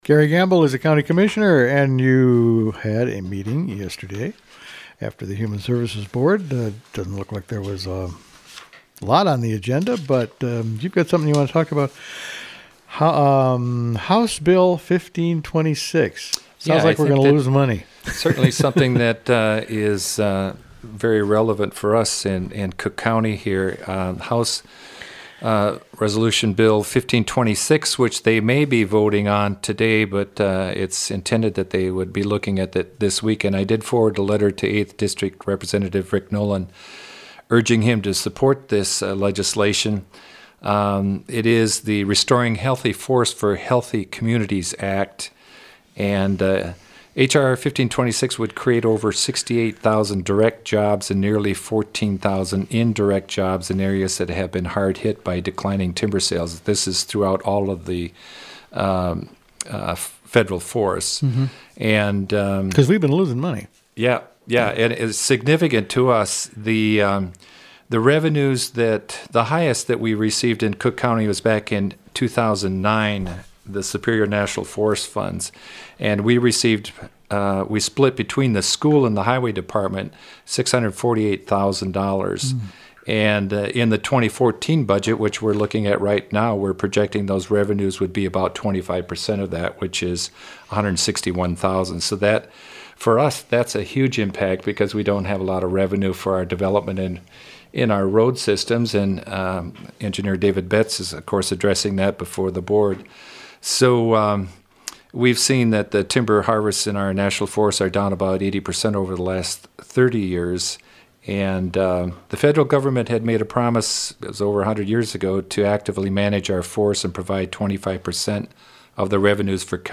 Commissioner Garry Gamble